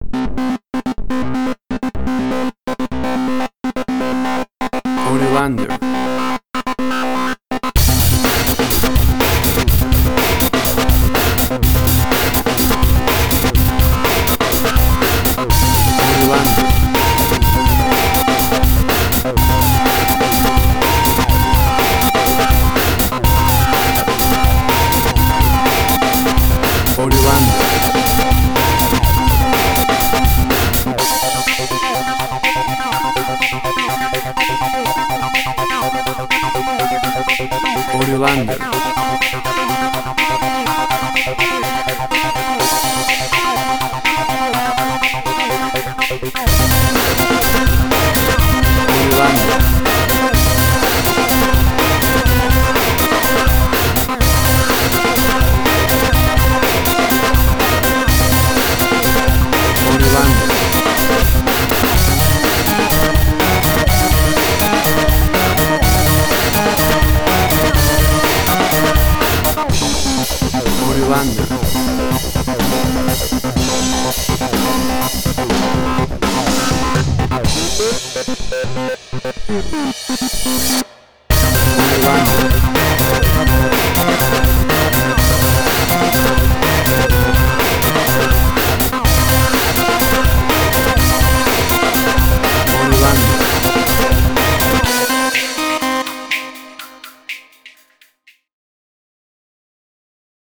Suspense, Drama, Quirky, Emotional.
Tempo (BPM): 124